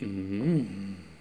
ajout des sons enregistrés à l'afk